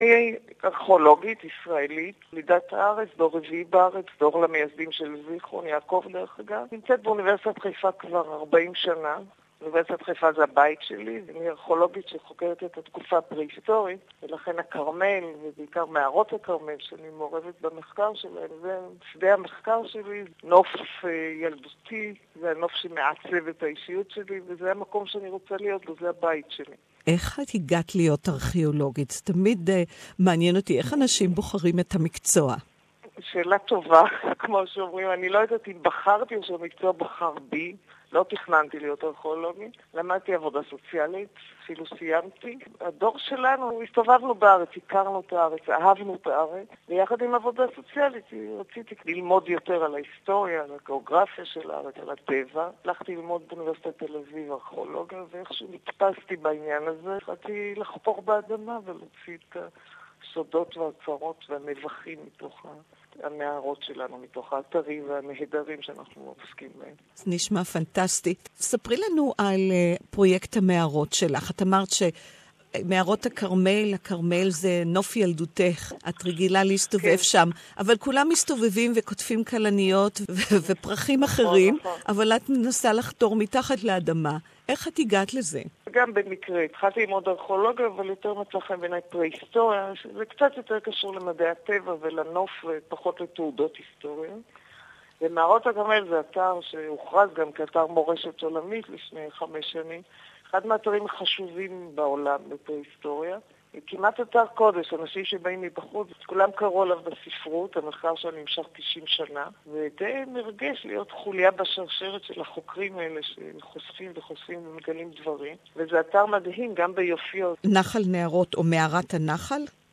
Interview in Hebrew